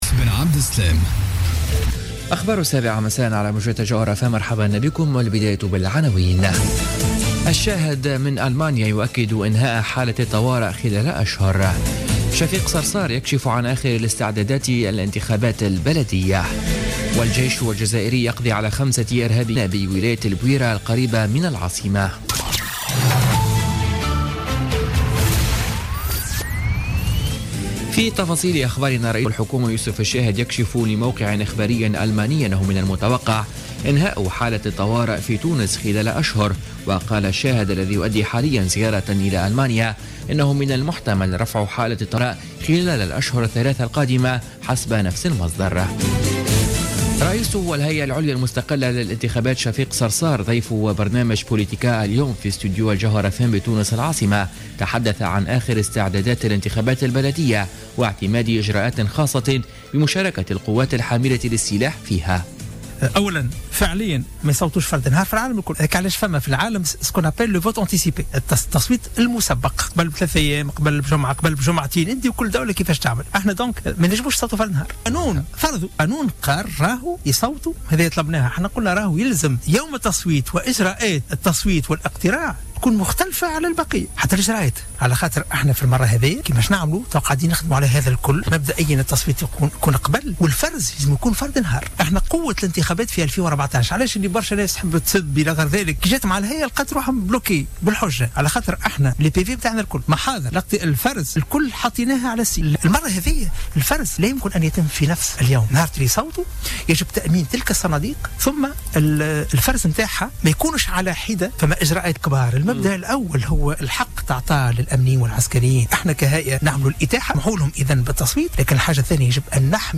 نشرة أخبار السابعة مساء ليوم الأربعاء 15 فيفري 2017